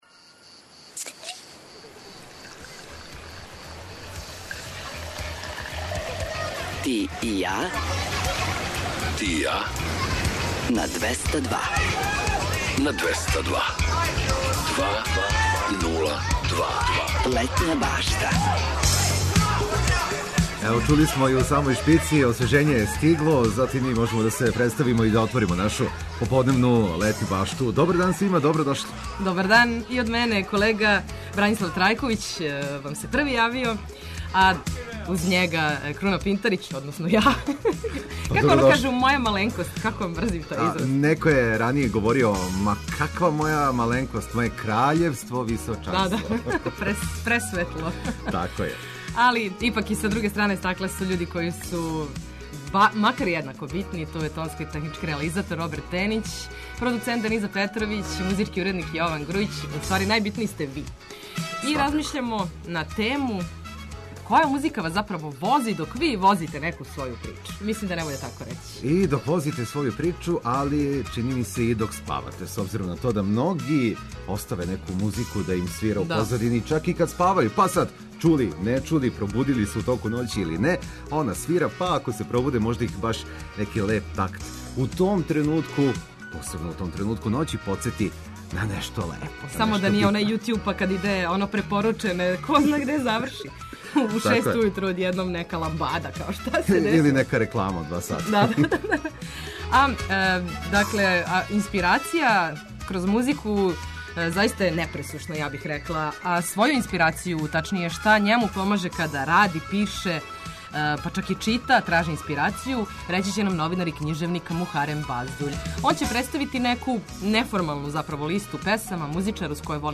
Поподне ћемо провести уз одличну музику, разговоре о филмовима и песмама, а биће ту и неколико предлога за летњи провод широм Србије.